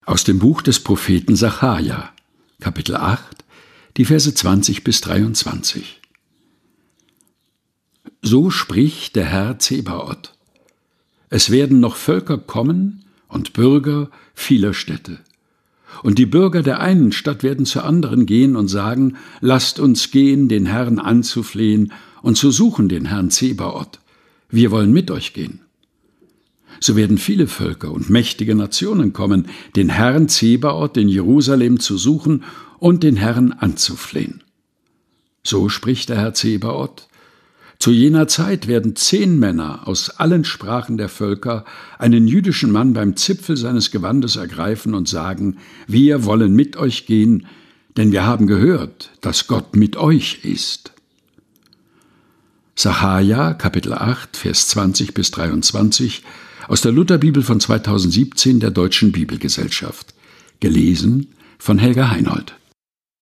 Predigttext zum 10.Sonntag nach Trinitatis des Jahres 2024.